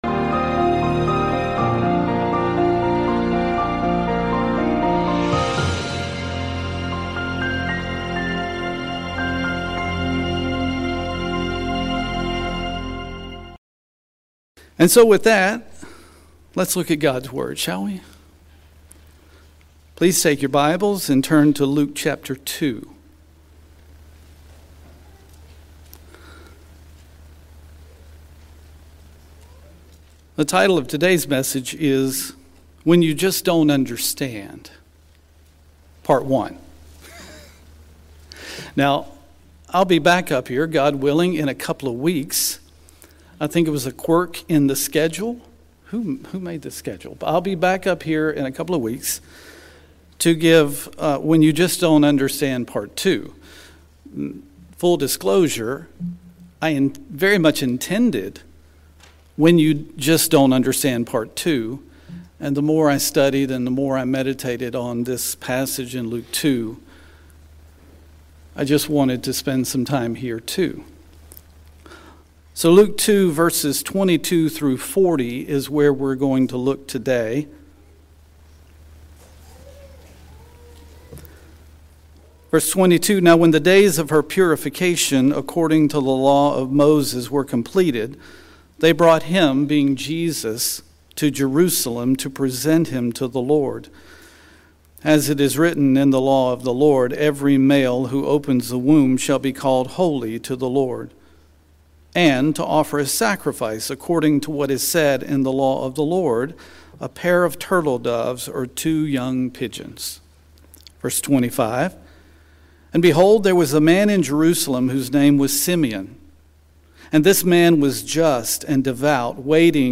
This sermon explores the account of two obscure biblical characters from Luke 2, Simeon and Anna. Their story reveals practices we can all adopt when we just don't understand.